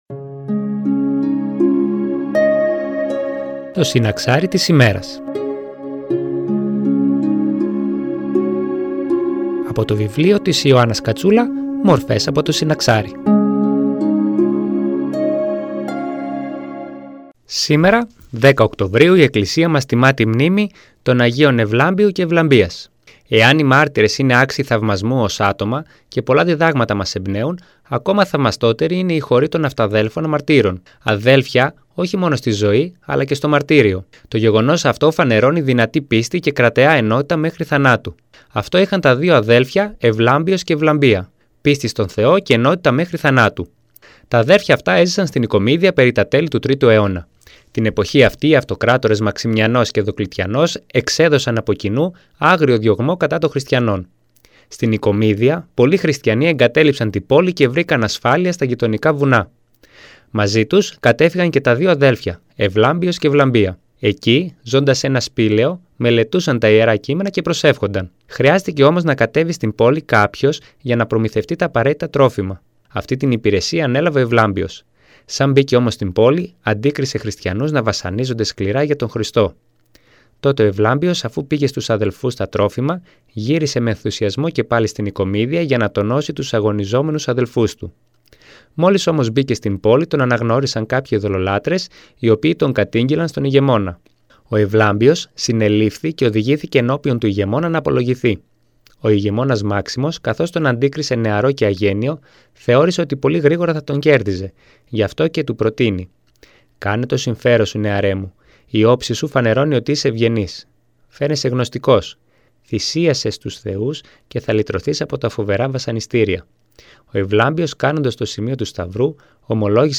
Μια ένθετη εκπομπή που μεταδίδεται από Δευτέρα έως Παρασκευή στις 09:25 από την ΕΡΤ Φλώρινας.
Εκκλησιαστική εκπομπή